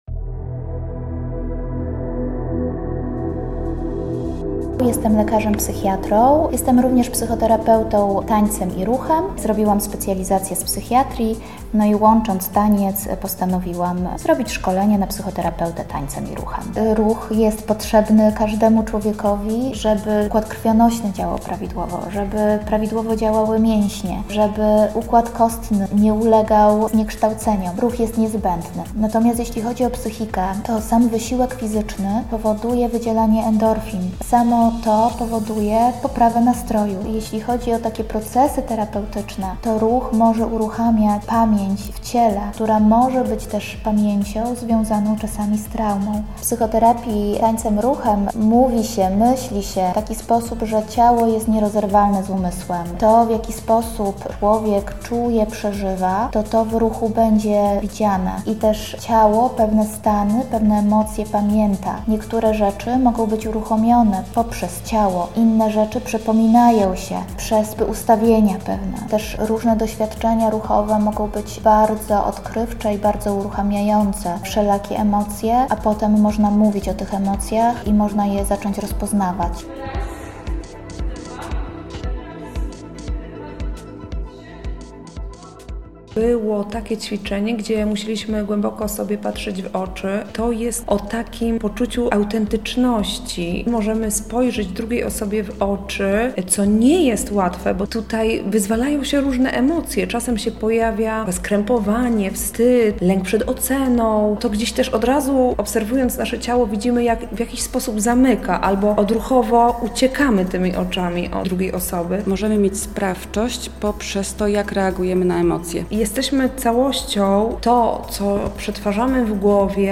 Swoim zadaniem podzieliły się też uczestniczki warsztatów rozwojowych „EmocJA w ruchu”.